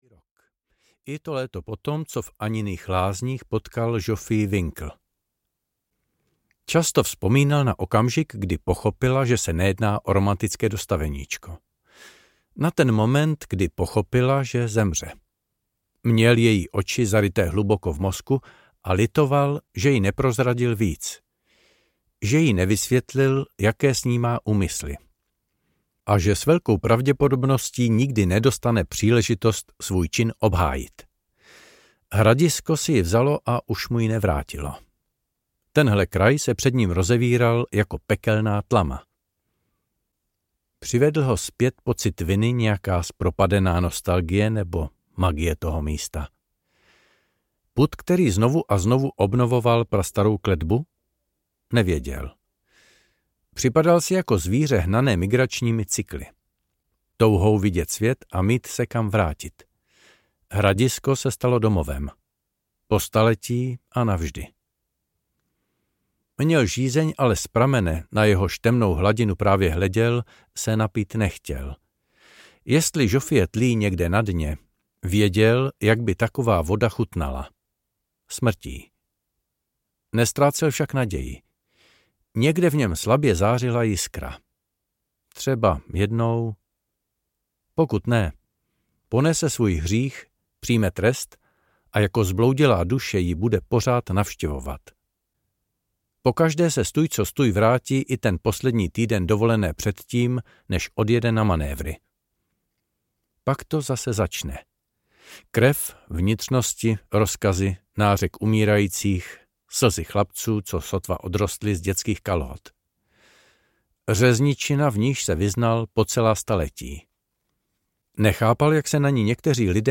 Steny záhrobí audiokniha
Ukázka z knihy